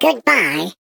Sfx_tool_spypenguin_vo_selfdestruct_04.ogg